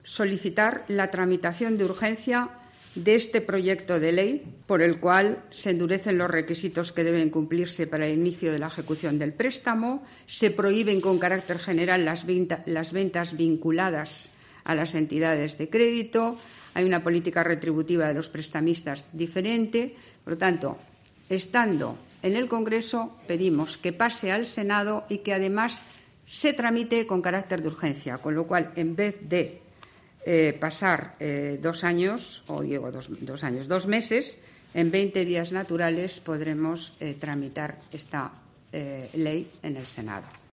La portavoz del Gobierno, Isabel Celaá, ha explicado en la rueda de prensa posterior al Consejo que esta medida se adopta para expresar "con toda contundencia y claridad" que el impuesto lo paga la banca.